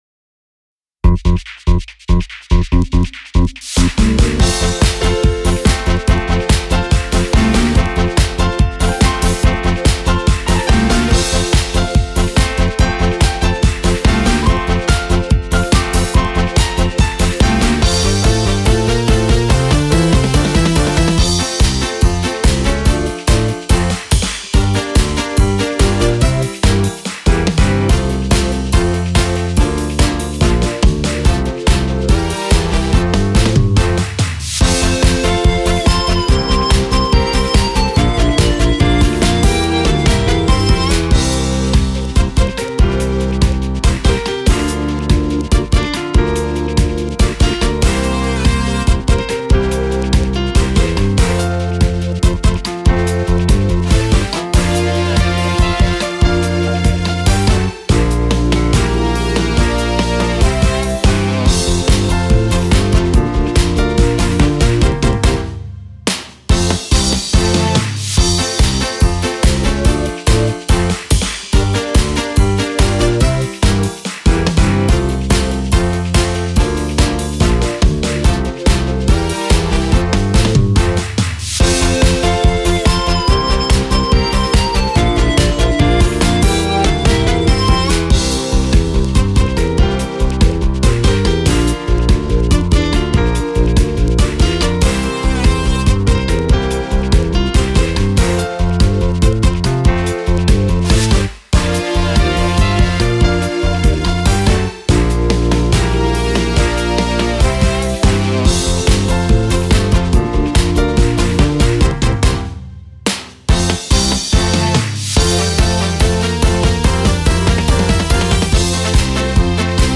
「ちょちょちょ、ちょちょちょ町内会」という耳に残る言葉が、明るく楽しい曲に乗って流れてきます。
掛け声付きカラオケ